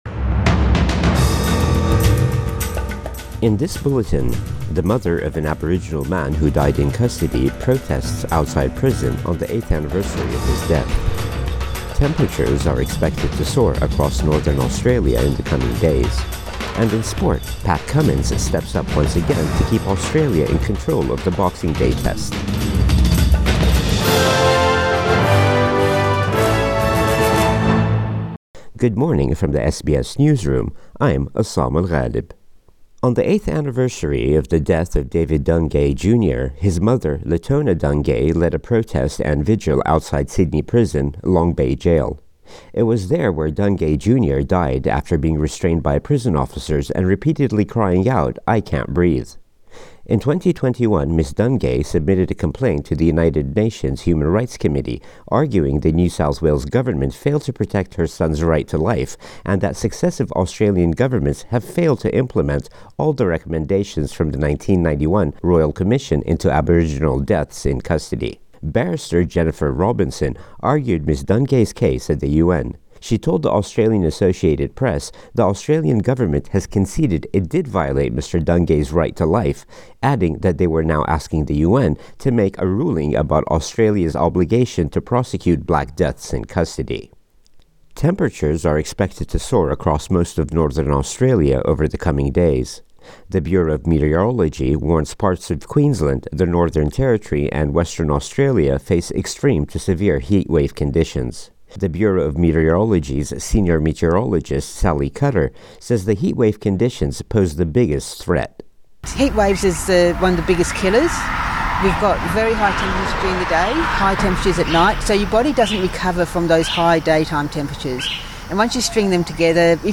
Morning News Bulletin 30 December 2023